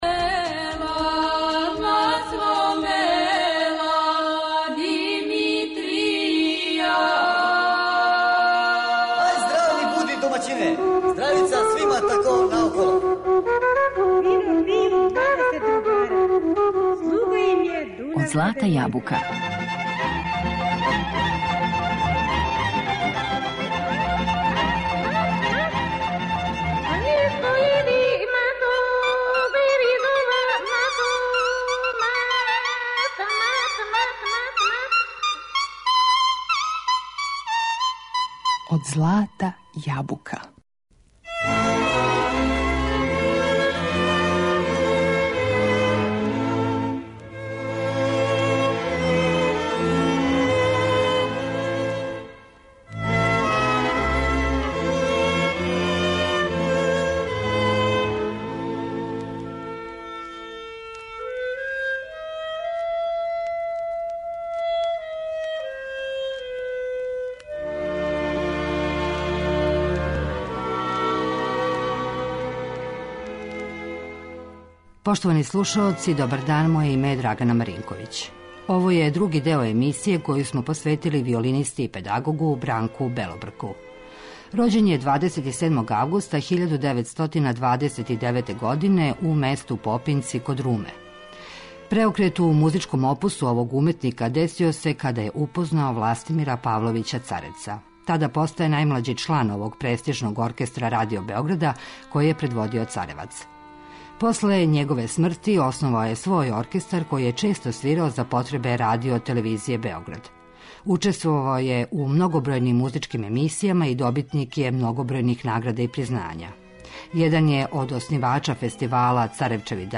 Музички портрет